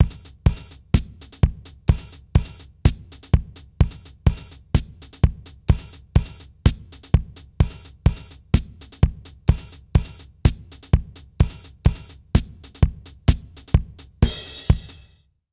British REGGAE Loop 132BPM - 3.wav